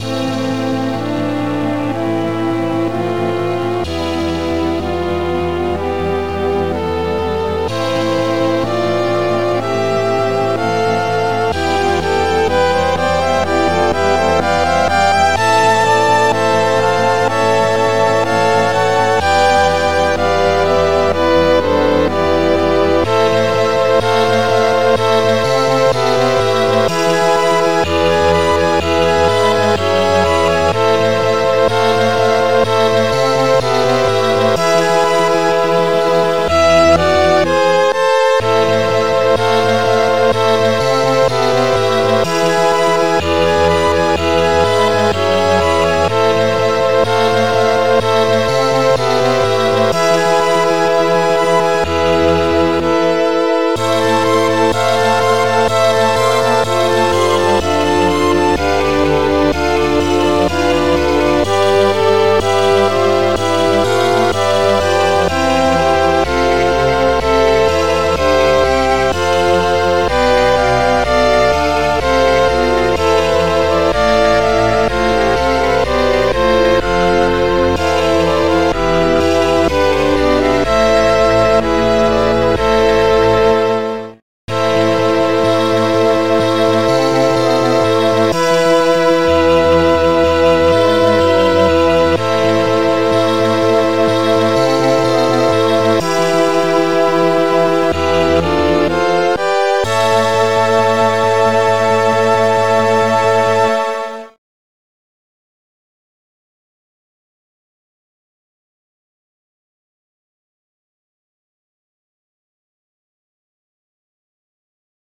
Extended Module
Type xm (FastTracker 2 v1.04)
Pipey thingys 303:Violin